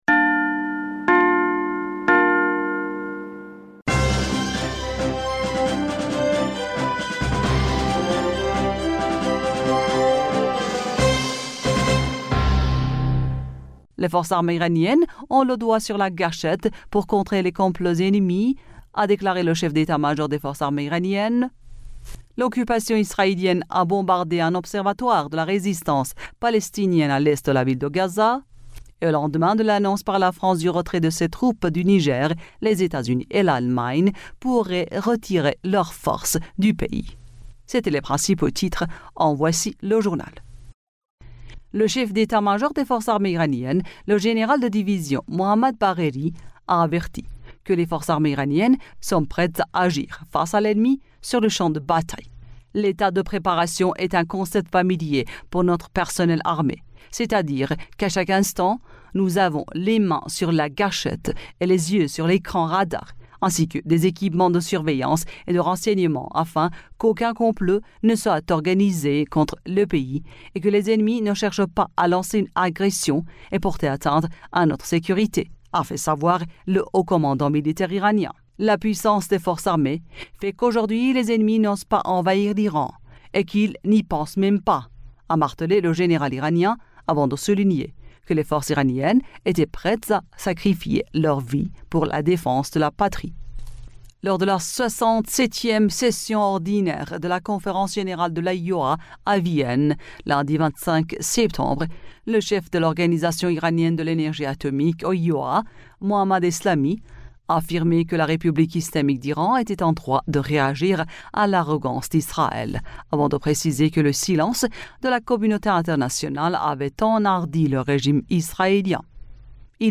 Bulletin d'information du 26 Septembre 2023